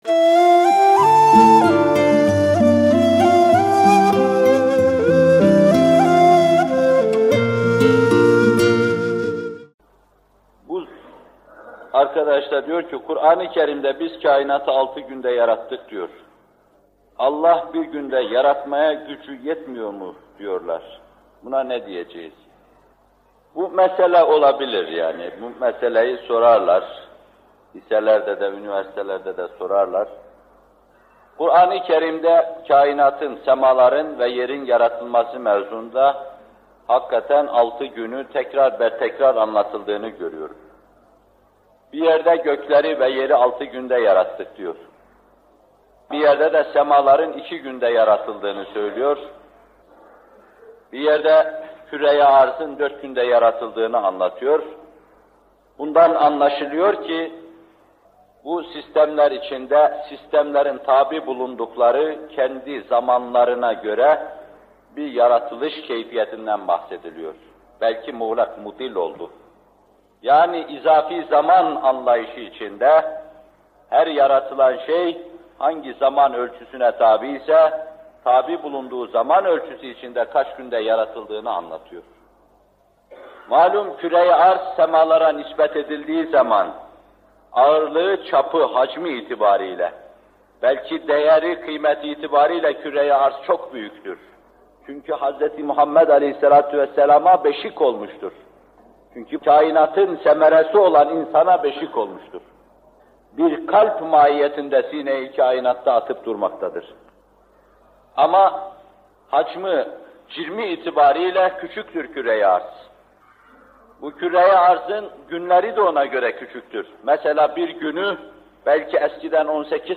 Muhterem Fethullah Gülen Hocaefendi bu videoda Furkan Suresi 59. ayet-i kerimesinin tefsirini yapıyor: